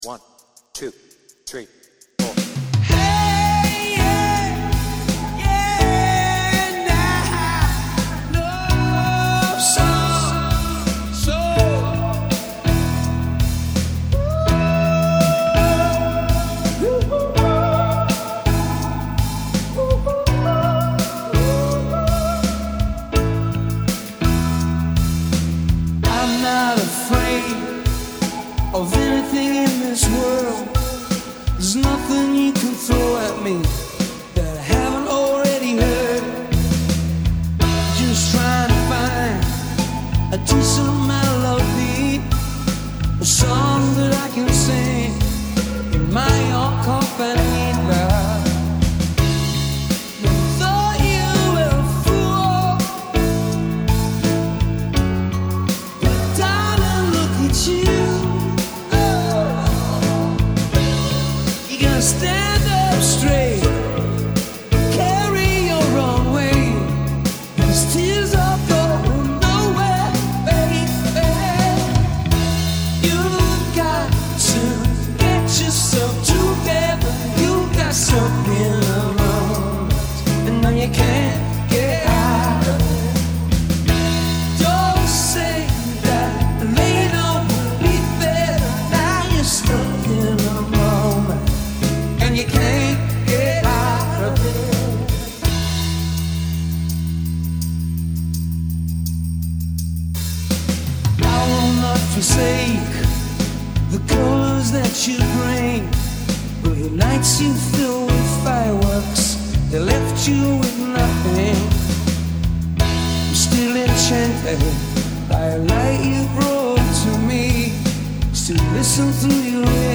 BPM : 83
Tuning : E
With Vocals